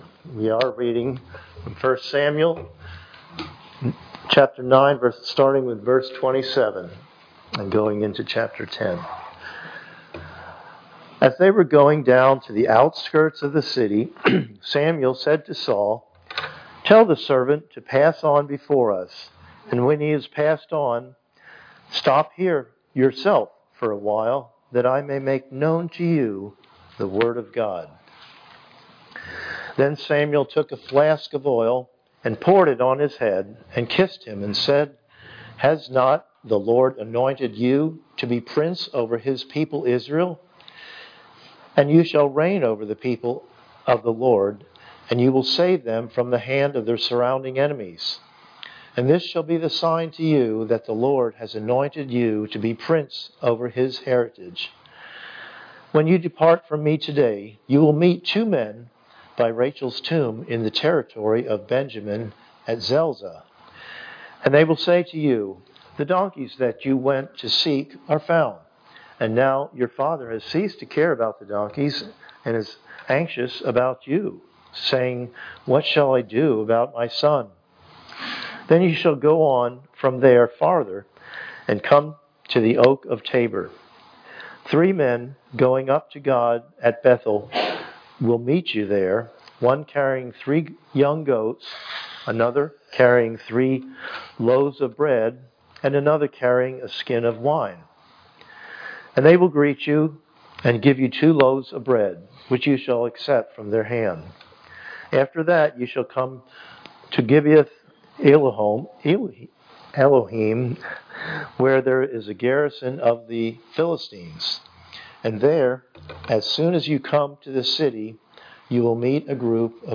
Passage: 1 Samuel 9:27-10:16 Service Type: Sunday Morning Worship